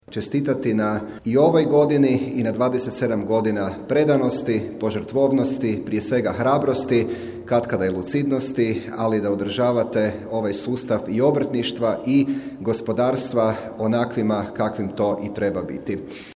Na svečanosti održanoj u Prelogu Obrtnička komora Međimurske županije obilježila je 27 godina rada i tom prilikom nagrađeni su uspješni obrtnici, o čemu smo izvijestili u našem programu.